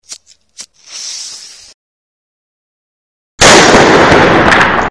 largefirework.mp3